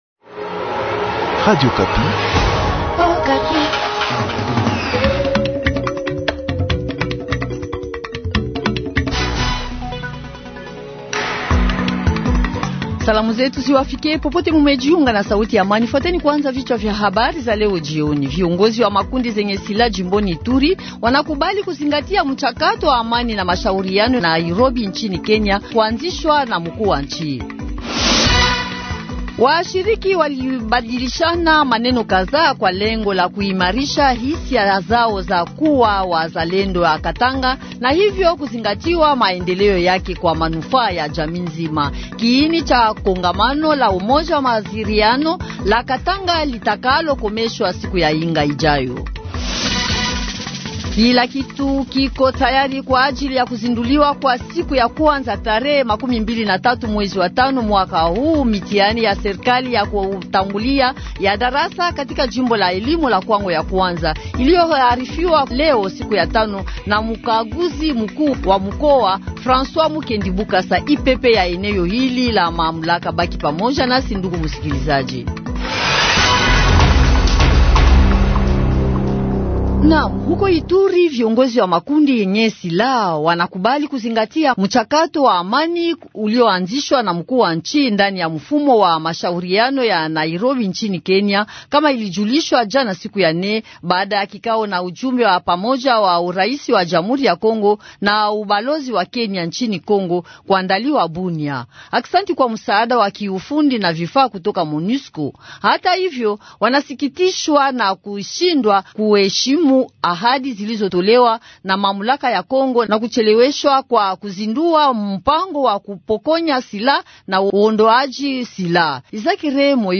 Journal Du Soir